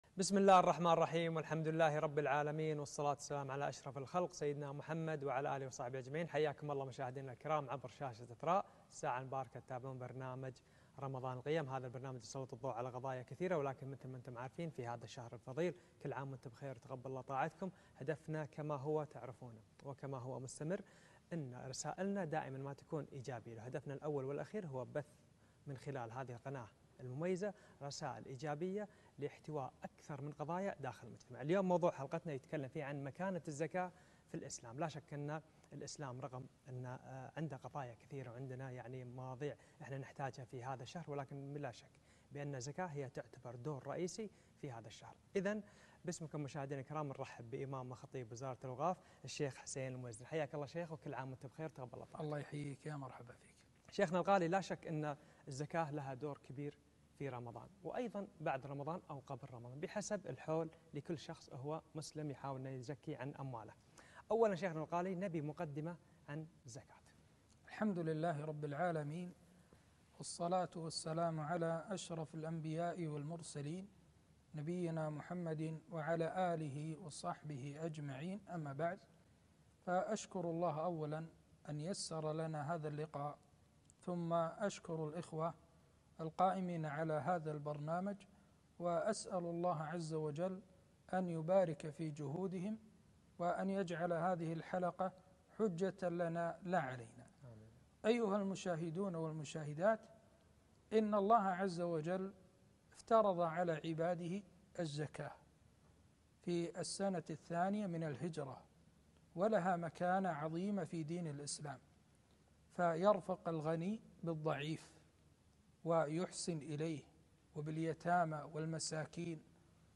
مكانة الزكاة في الإسلام - لقاء على قناة إثراء